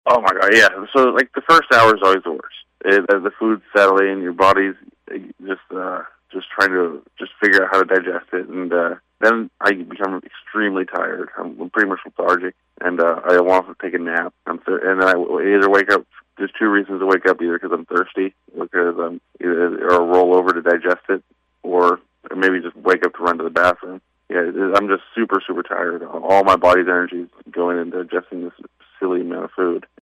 In an interview with KWON Sports Chestnut described what it is like for his body in the hours after consuming all those dogs.